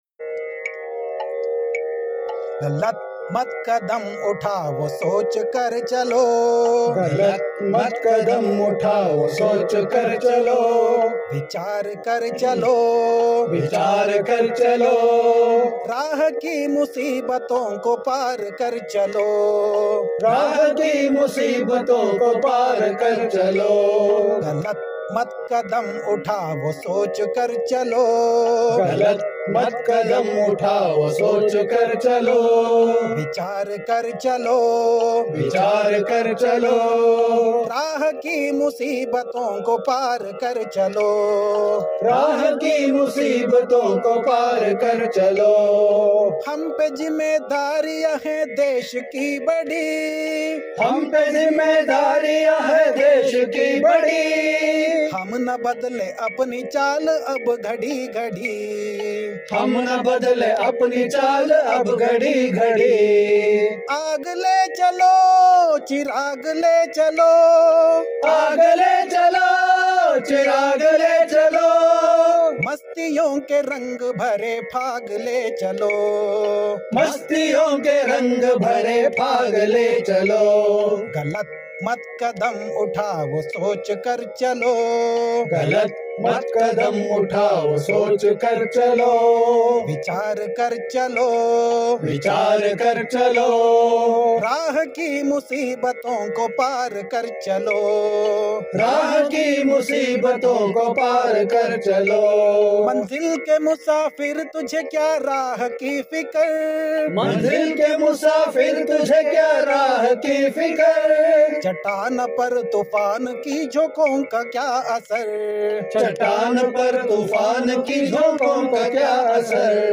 Patriotic Songs Collections